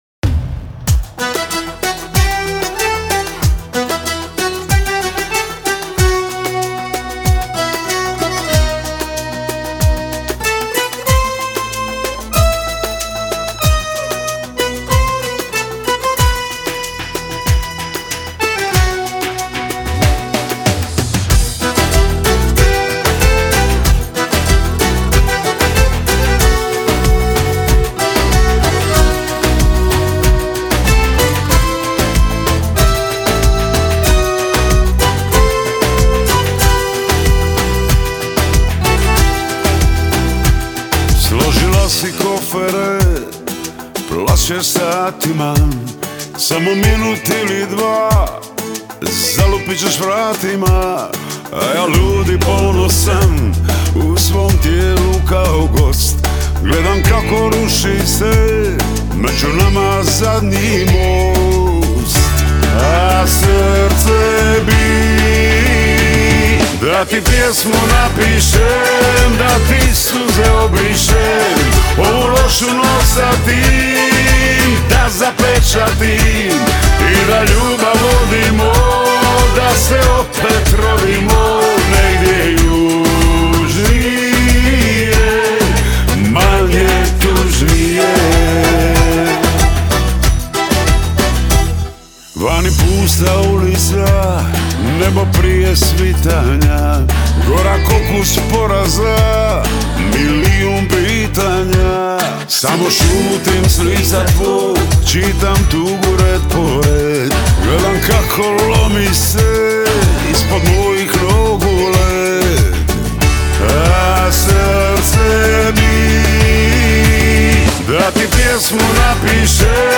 Izjemno dinamična pesem
dobre tekste in življenjske ter nalezljive glasbene ritme.
Singel